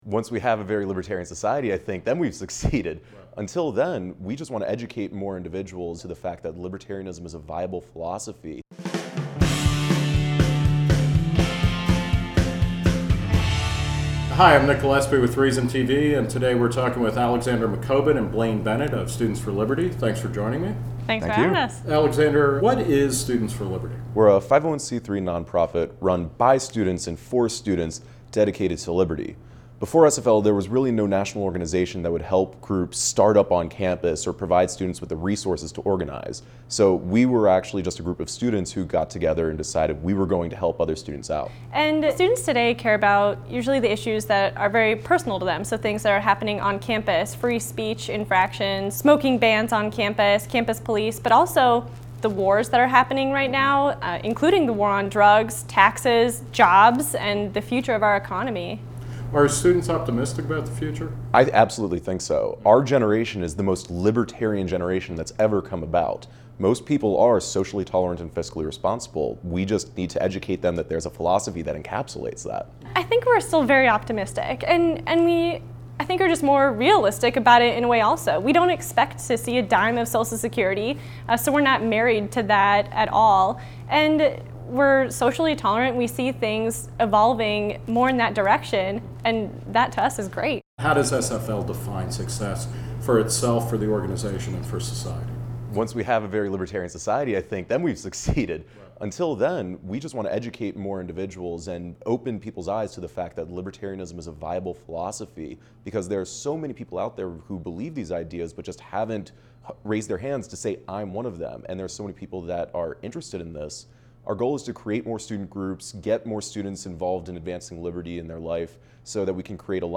Hosted by Nick Gillespie.